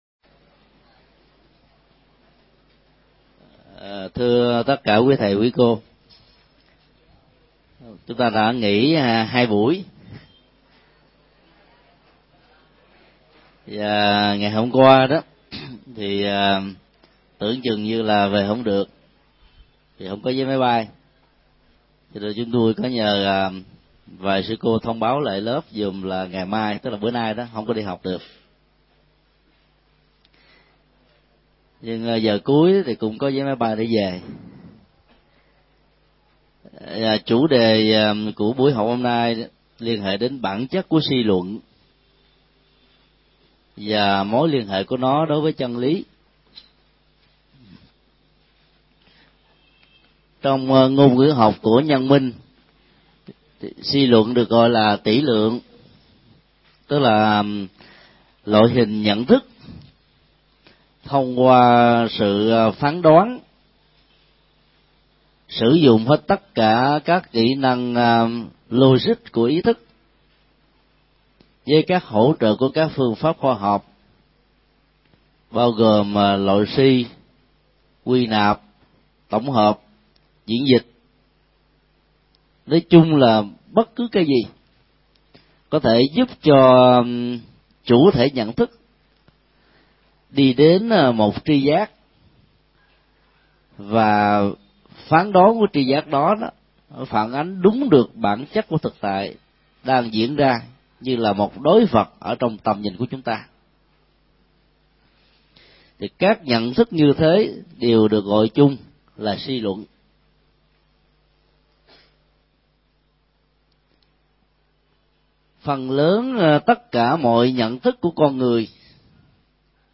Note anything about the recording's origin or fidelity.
Giảng tại Học viện Phật giáo Việt Nam tại TP.HCM